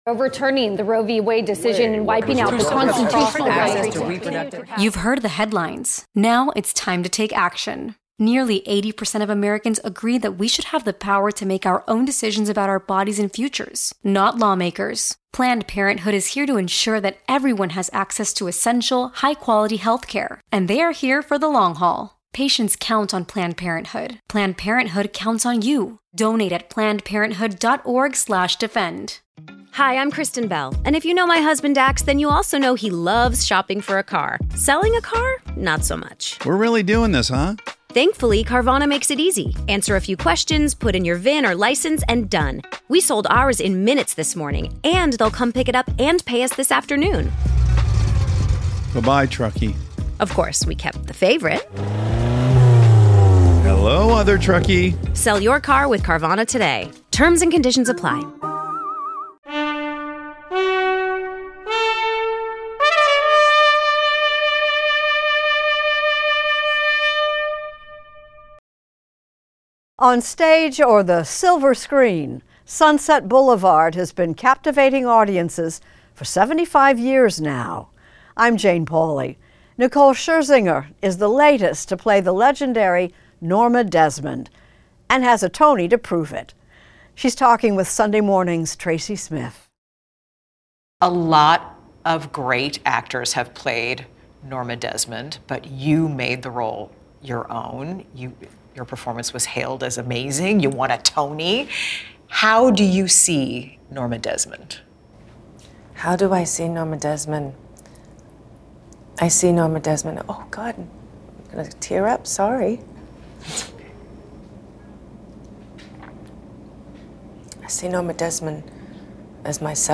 Extended Interview - Nicole Scherzinger
Nicole Scherzinger, who won a Tony Award for the recent Broadway revival of Andrew Lloyd Webber's "Sunset Boulevard," talks with correspondent Tracy Smith about playing the "beautiful complexity" of femme fatale Norma Desmond. She also discusses the musical's themes of ageism in the entertainment industry; her early theater experiences; and why the original film's tragic story still resonates today.